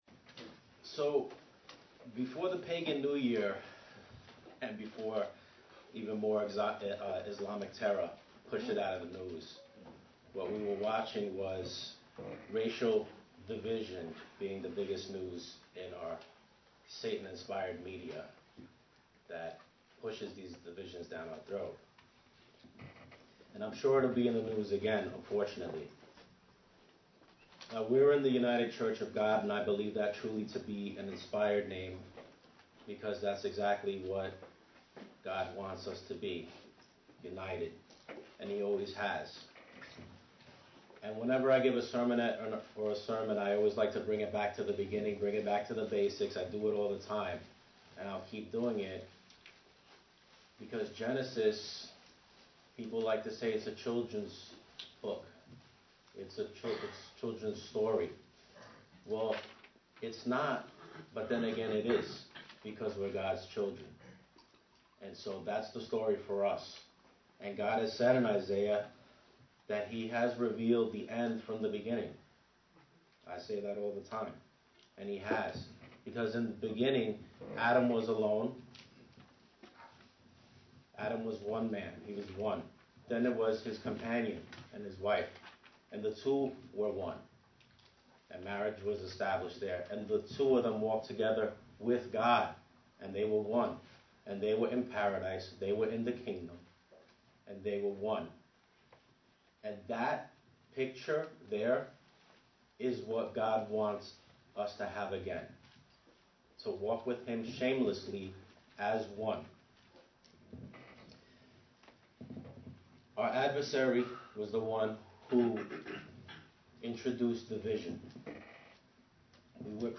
Given in New York City, NY
UCG Sermon Studying the bible?